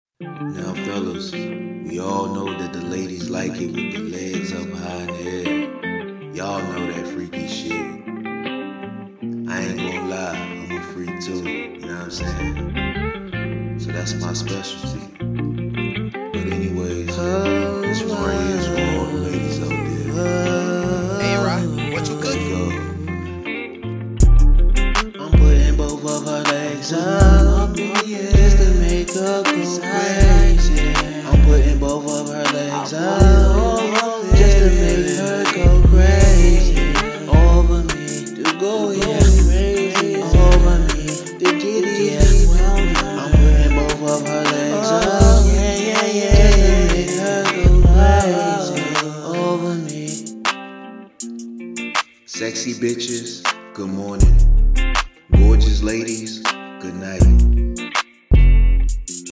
Rap
it's gives you one of those sexy vibes